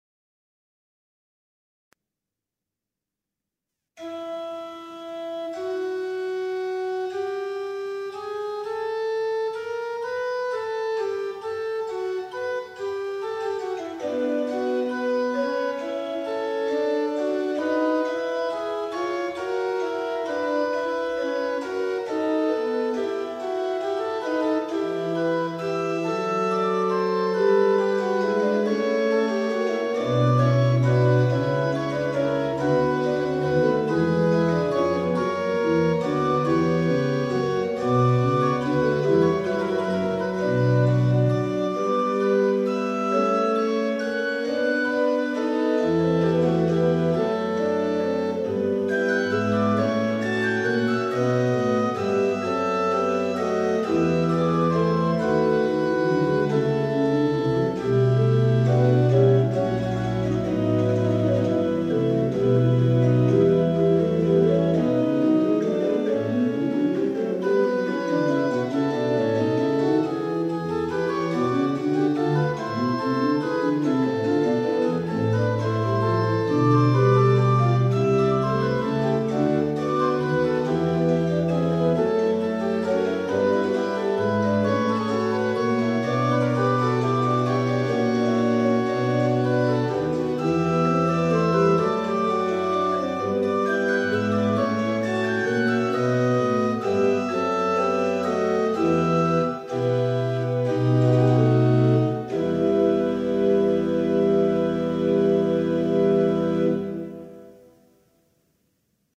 Fugue in E minor BWV 555 by JS Bach  —  1 minute 48 seconds
fugue-in-e-minor-bwv-555-by-js-bach.mp3